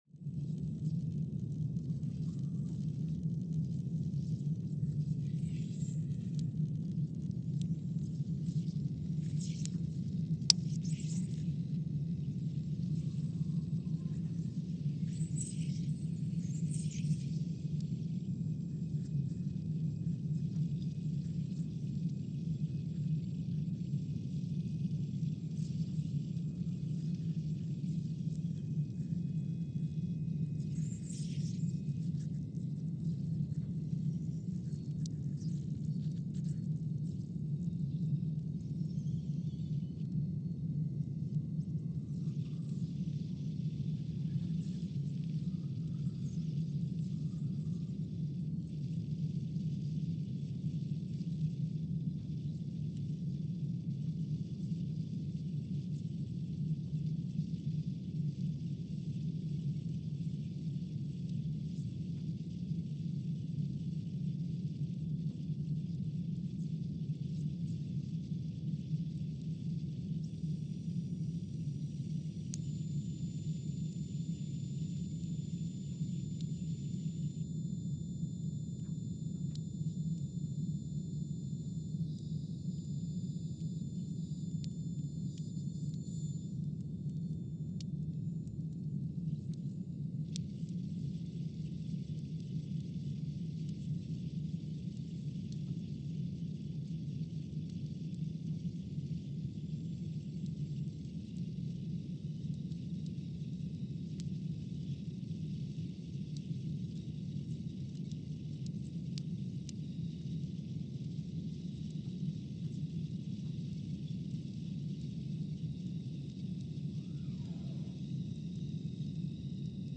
Scott Base, Antarctica (seismic) archived on February 19, 2020
No events.
Sensor : CMG3-T
Speedup : ×500 (transposed up about 9 octaves)
Loop duration (audio) : 05:45 (stereo)
SoX post-processing : highpass -2 90 highpass -2 90